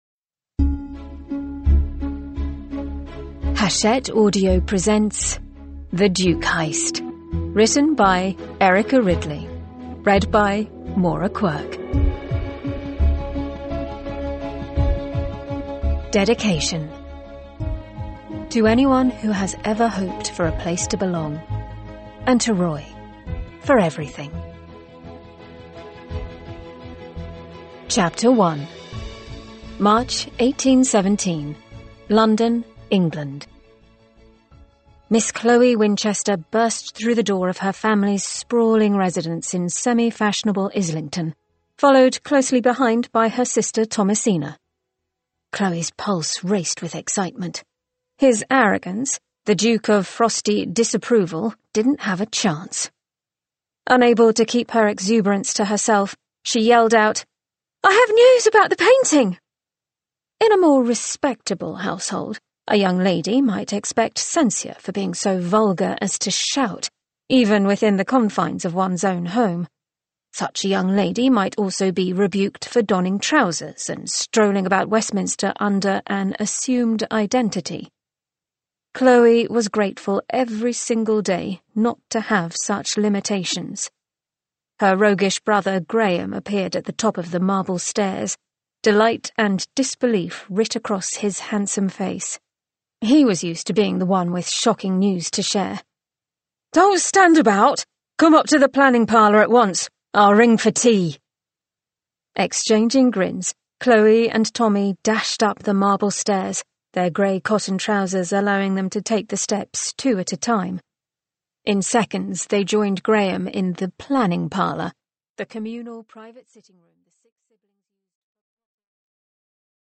Edition: Unabridged.
Audiobooks.